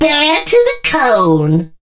lou_kill_vo_01.ogg